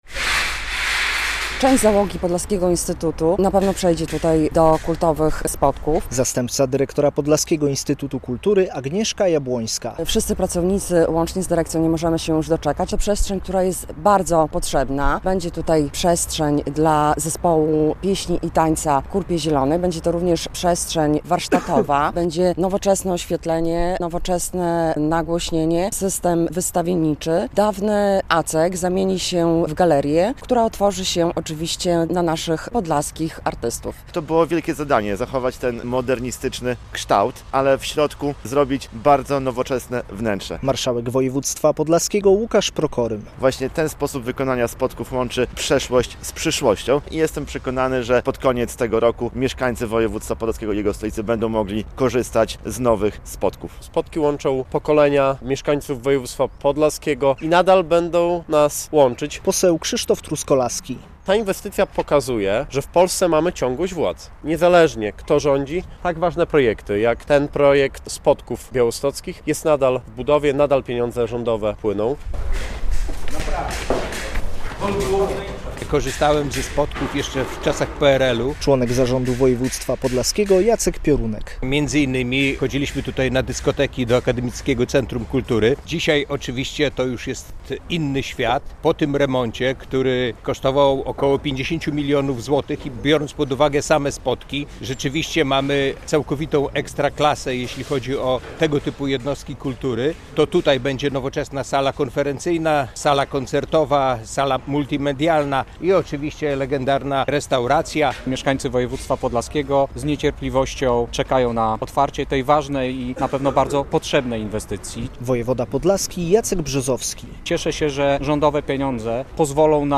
Białostockie Spodki zmodernizowane za 50 mln zł. Otwarcie pod koniec roku - relacja